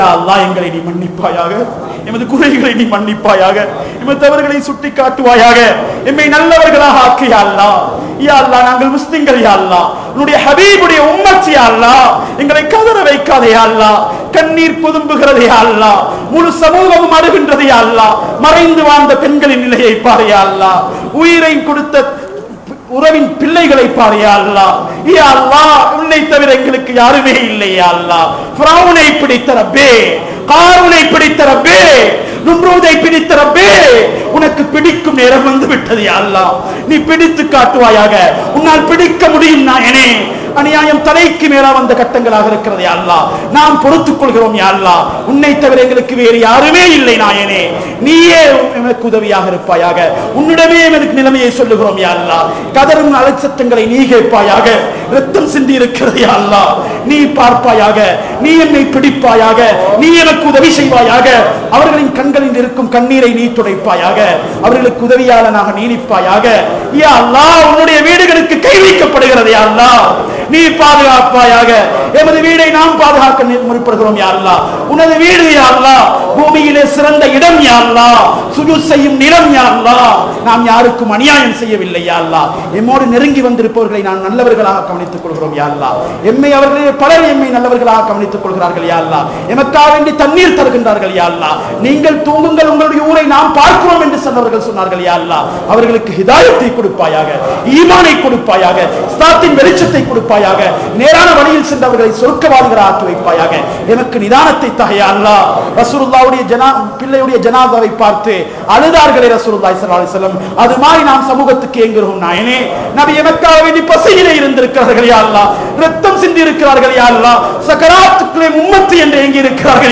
Bayans
Colombo 11, Samman Kottu Jumua Masjith (Red Masjith)